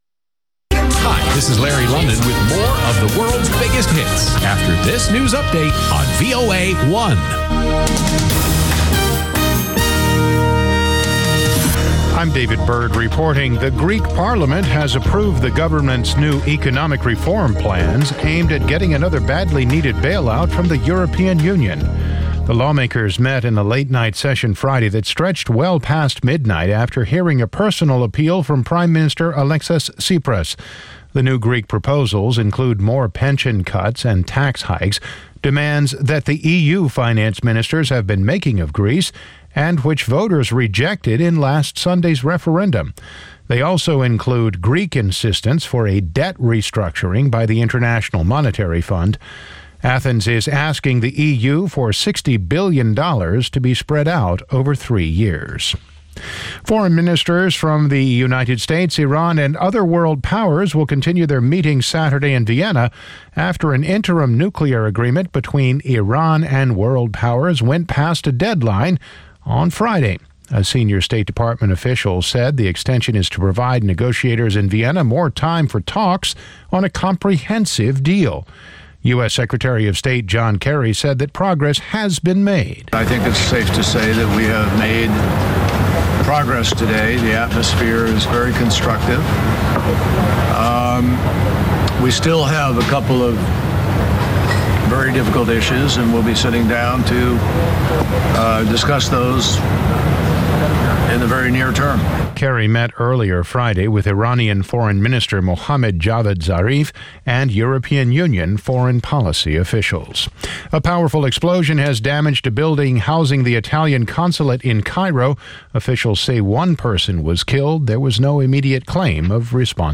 "ამერიკის ხმის" ახალი ამბები (ინგლისურად) + VOA Music Mix